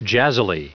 Prononciation du mot jazzily en anglais (fichier audio)
Prononciation du mot : jazzily